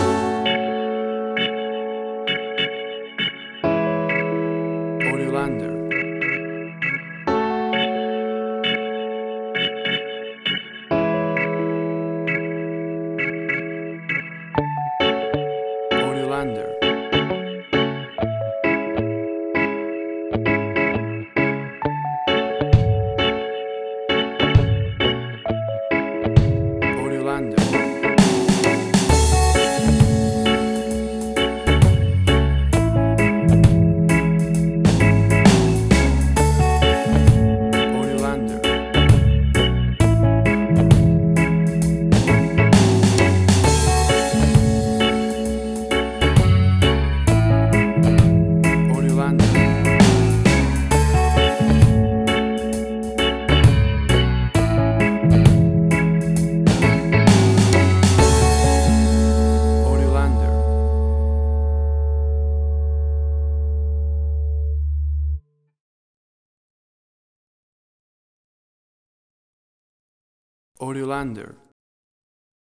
A chilled and relaxed piece of smooth reggae music!
Tempo (BPM): 66